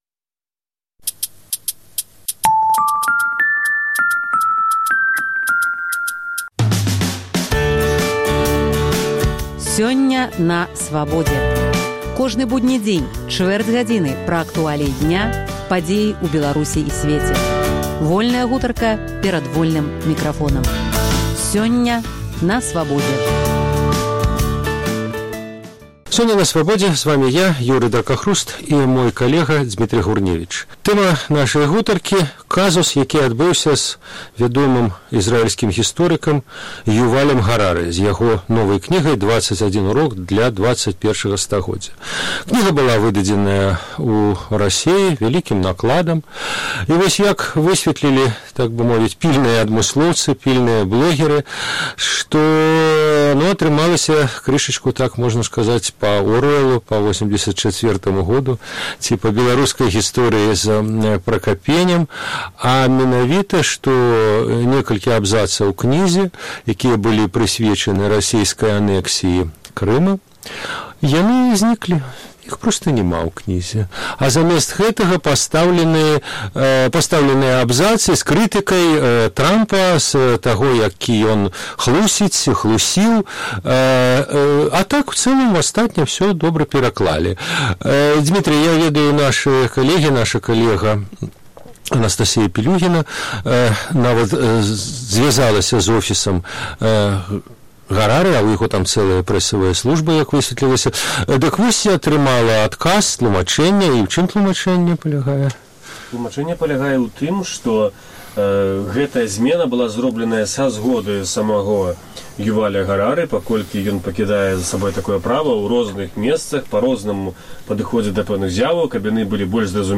Інтэрвію Свабоды.